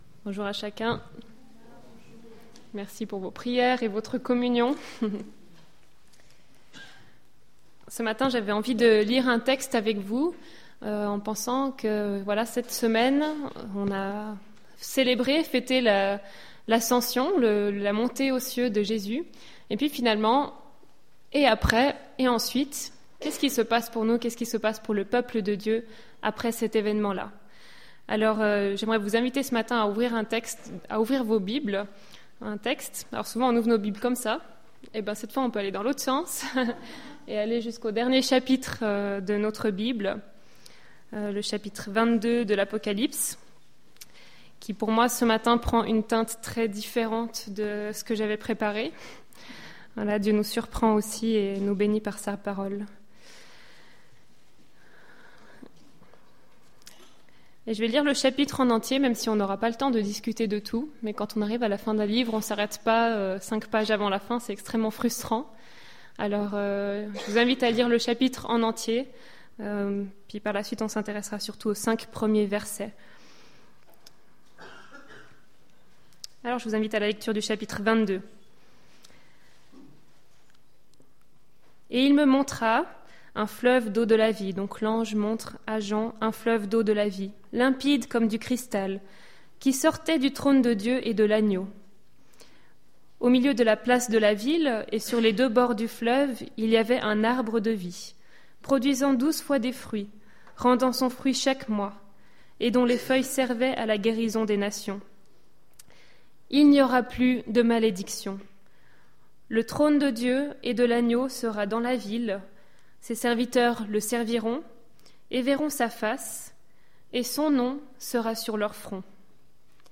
Culte du 8 mai 2016